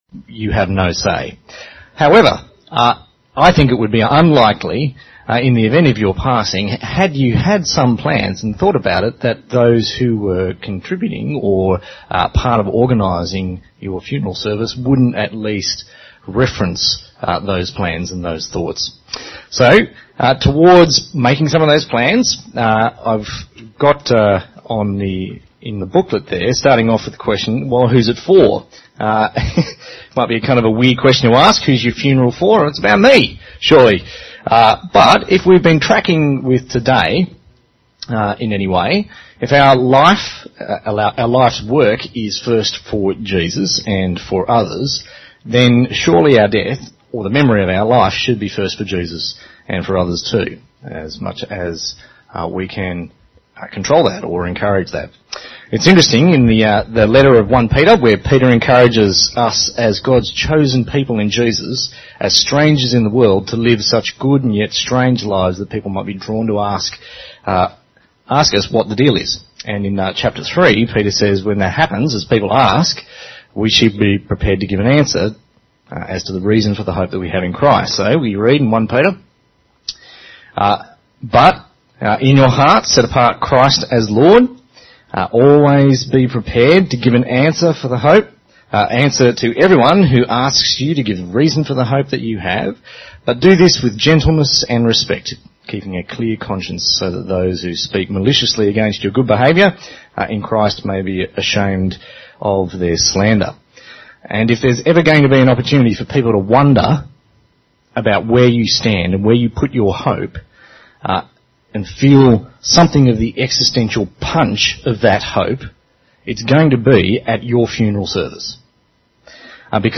Finishing Well Seminar: Funeral Planning